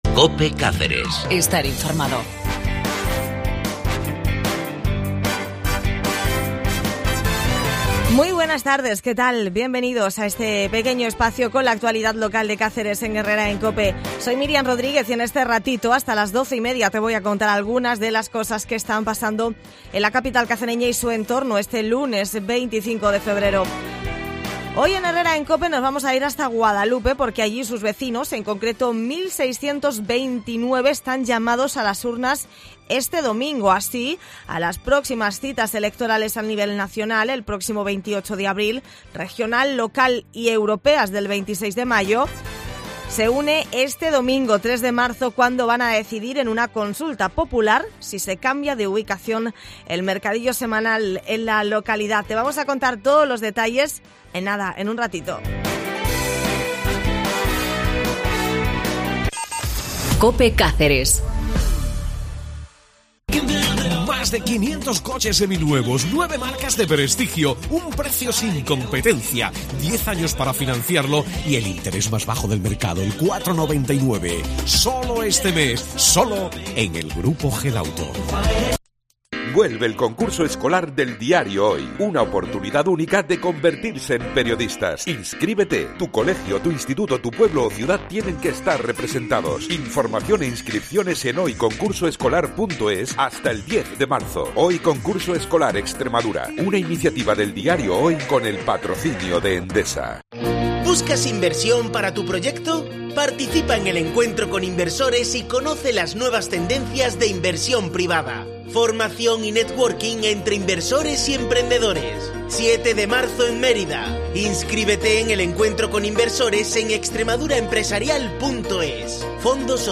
Así a las próximas citas electorales, las Generales del próximo 28 de abril, Autonómicas, Locales y Europeas, del 26 de mayo, se úne este domingo 3 de marzo cuando decidirán en una Consulta Popular el cambio de ubicación el mercadillo semanal. En Herrera en Cope hemos hablado con el alcalde de la localidad, Felipe Barba.